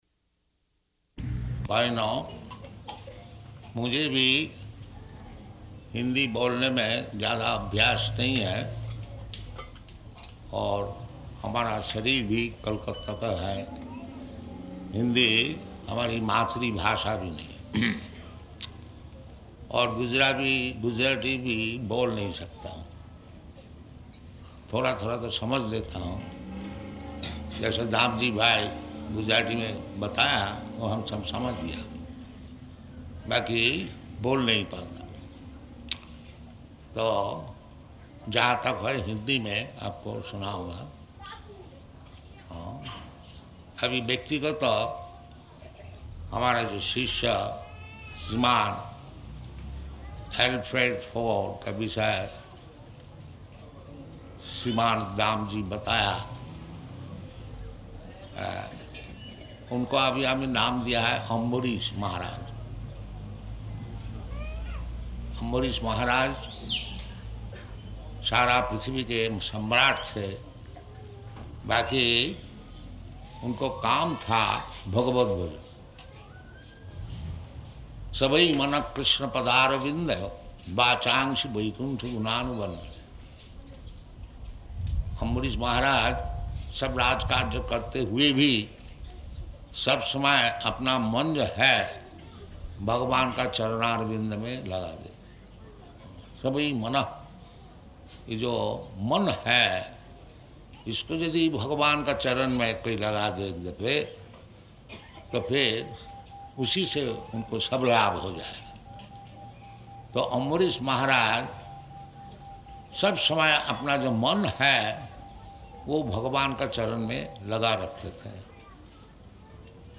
Lecture in [Hindi--final proofreading pending]
Type: Lectures and Addresses
Location: Bombay